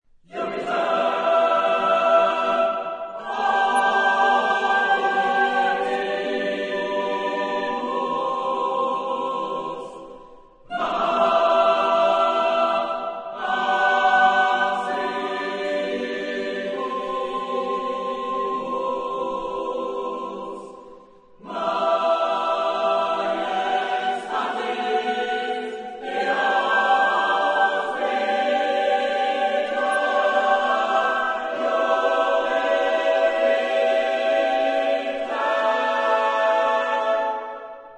Genre-Stil-Form: weltlich ; Motette
Charakter des Stückes: hervorgehoben ; schwer
Chorgattung: SSAATTBB  (8 gemischter Chor Stimmen )
Tonart(en): frei
Lokalisierung : 20ème Profane Acappella